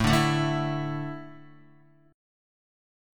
Asus4 chord